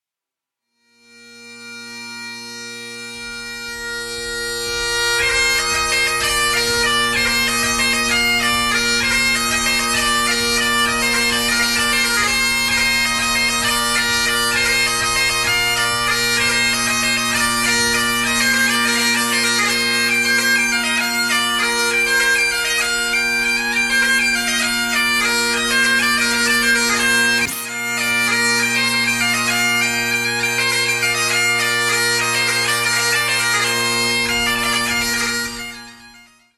gaita.1.mp3